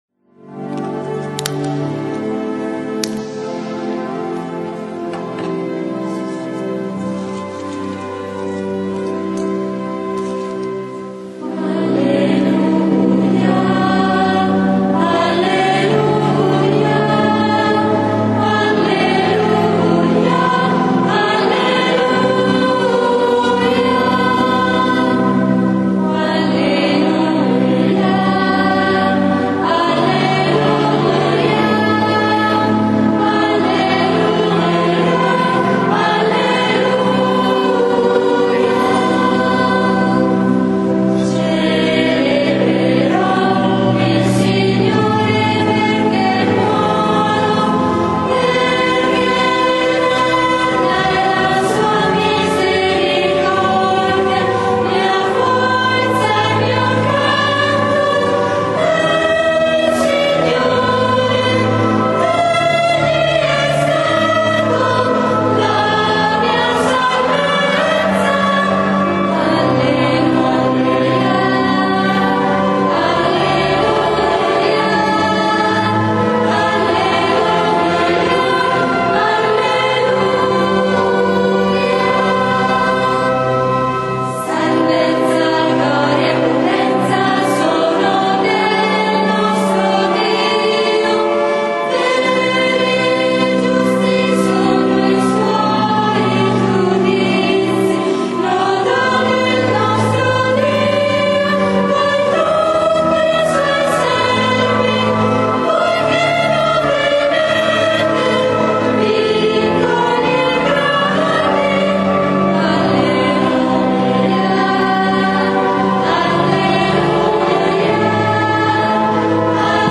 SABATO SANTO -Celebrazione della Resurrezione del Signore-
canti: